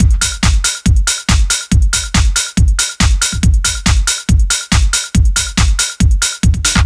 NRG 4 On The Floor 010.wav